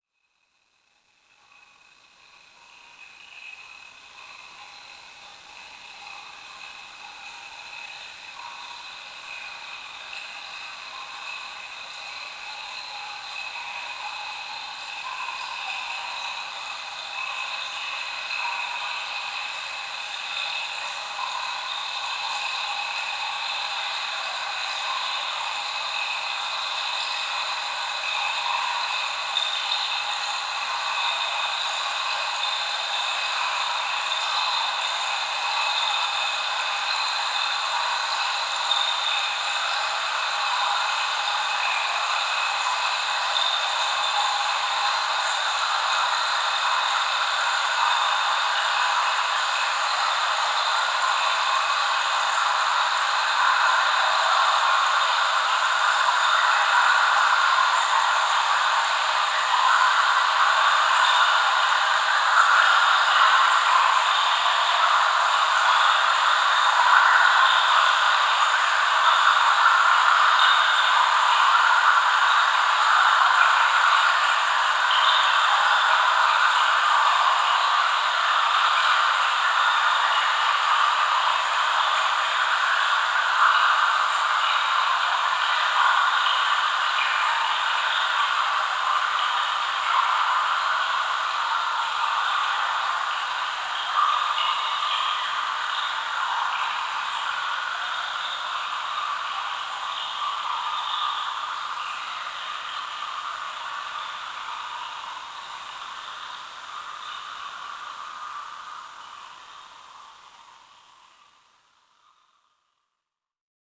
Well*Trickle.aif